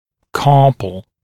[‘kɑːpl][‘ка:пл]запястный, кистевой